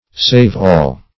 Save-all \Save"-all`\, n. [Save + all.]